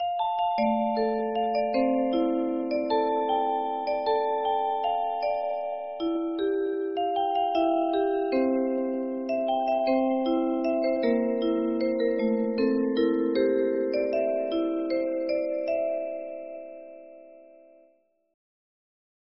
- COLOR HANDLE CRANK MUSIC BOX MECHANISMS